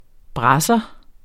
Udtale [ ˈbʁɑsʌ ]